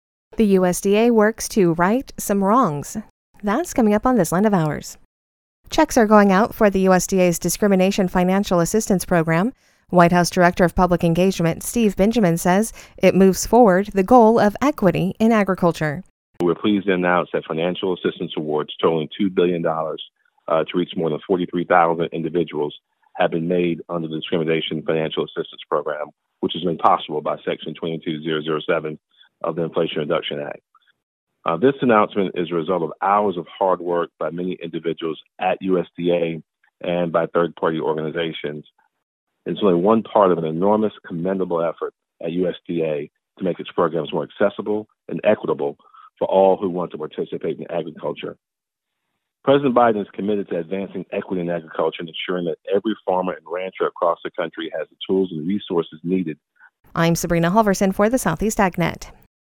Checks are going out for the USDA’s discrimination financial assistance program. White House Director of Public Engagement Steve Benjamin says it moves forward the goal of equity in agriculture.